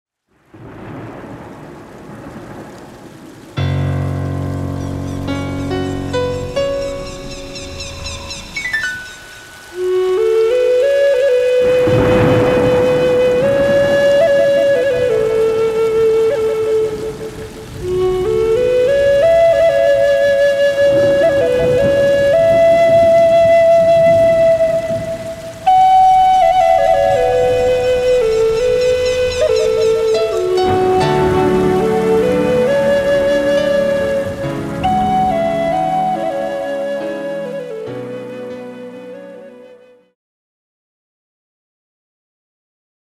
More soothing melodies for relaxing and massage therapy.